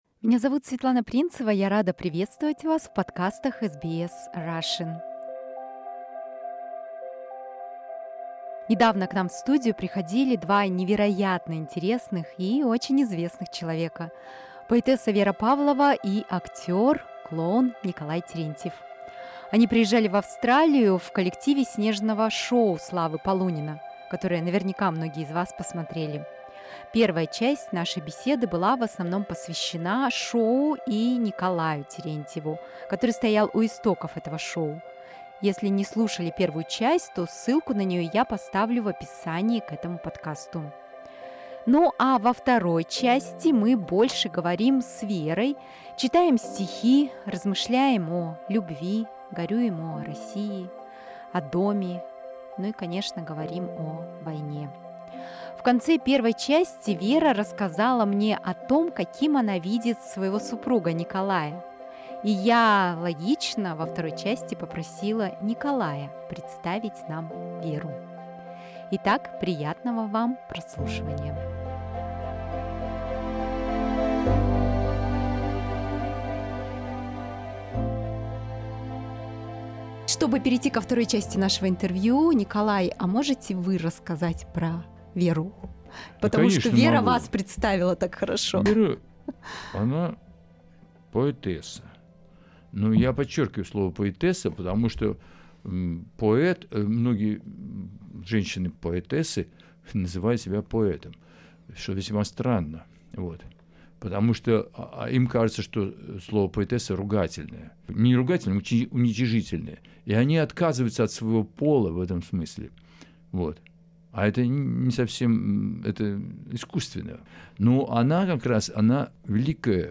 Беседа
в студии SBS Russian